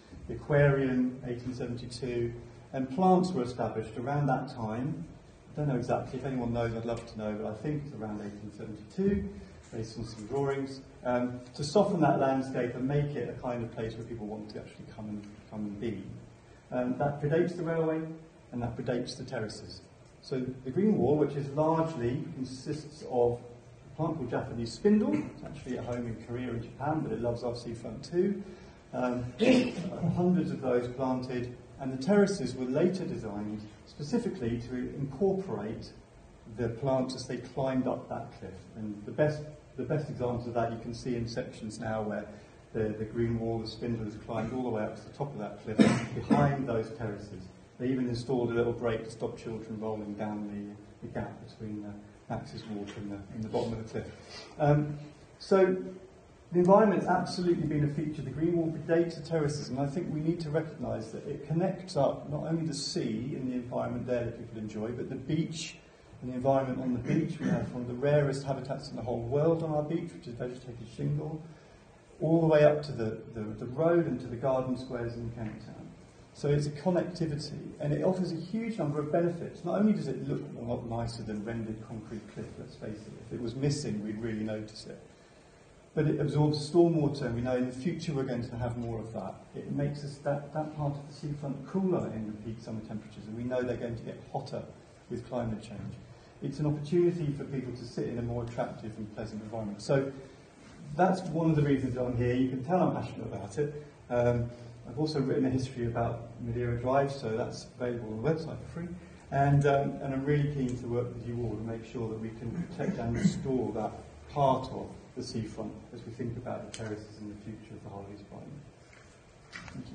Last night Building Green attended a meeting of around 40 people interested in the future of Madeira Terraces and the regeneration of East Brighton seafront.